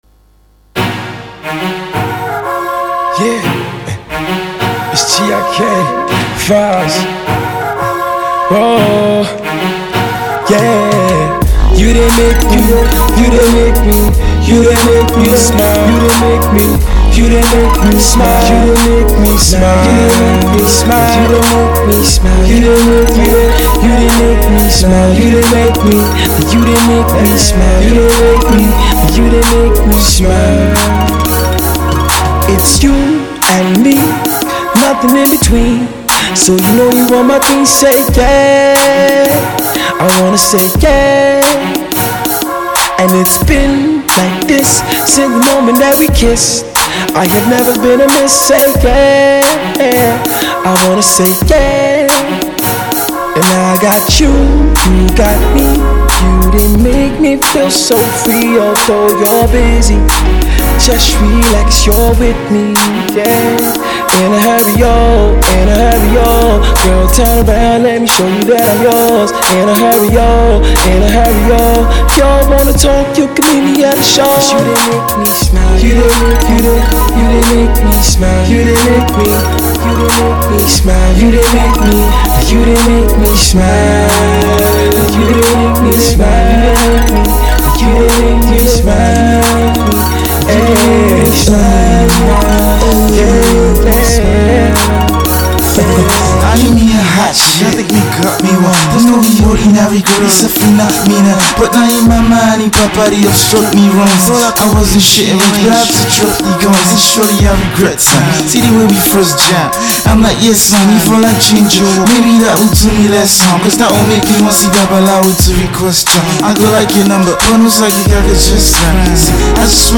New heat from the homie and highly-talented R & B artiste